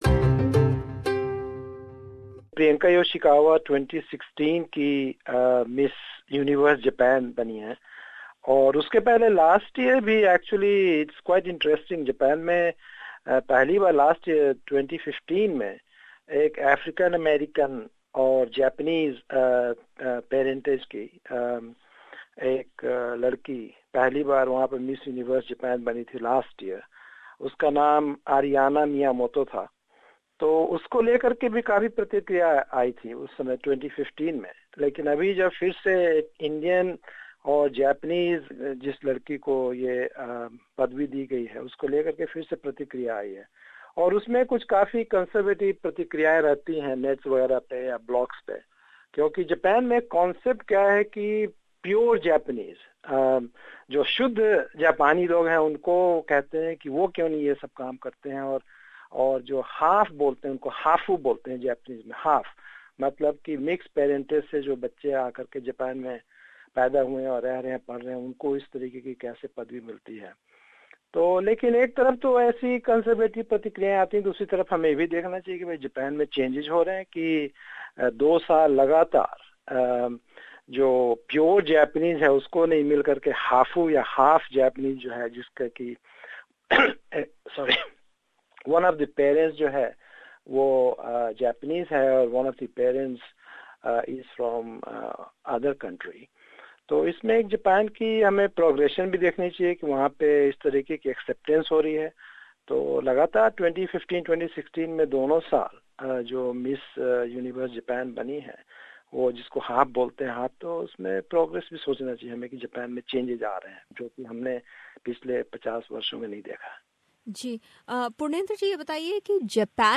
Her victory drew mixed reactions in Japan. We spoke to expet on Japan.